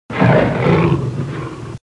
Cougar Sound Effect
cougar.mp3